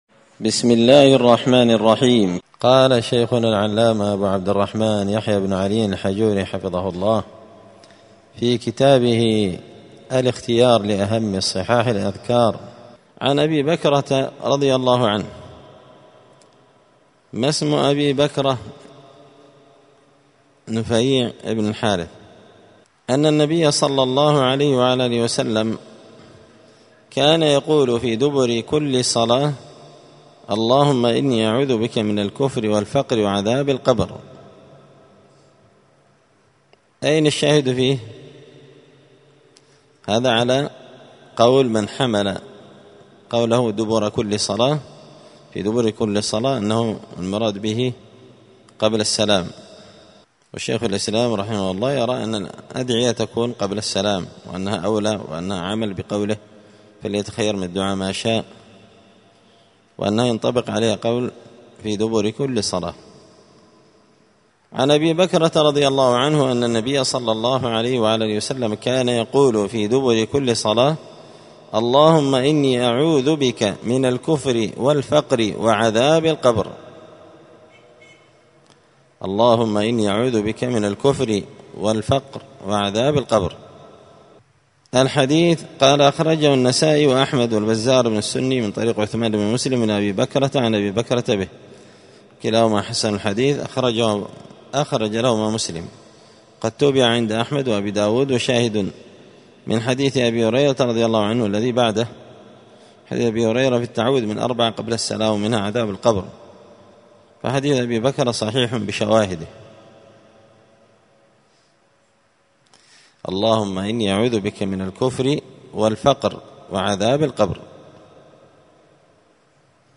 الاختيار لأهم صحاح الأذكار للعلامة يحيى الحجوري الدرس السادس والثلاثون (36) أذكار الصلاة الذكر بعد الصلاة